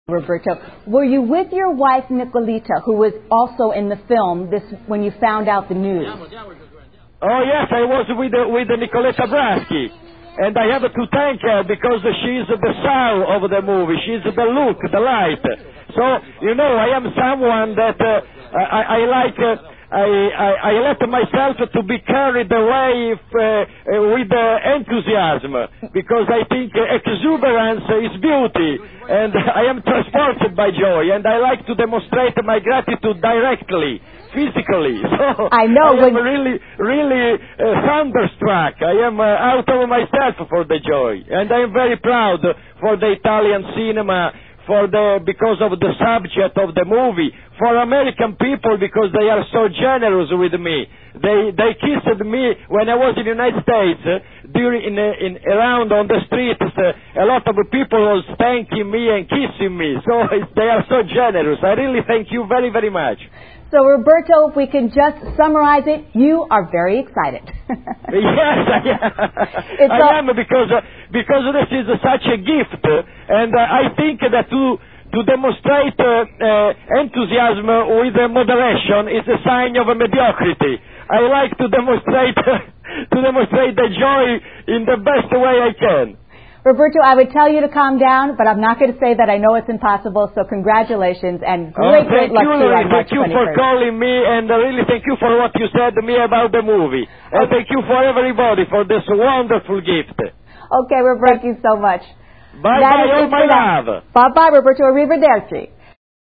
intervistadopooscar01.mp3